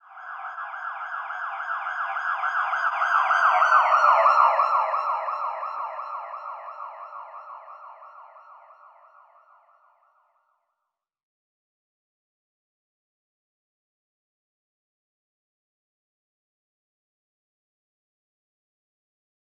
Hands Up - Siren 02.wav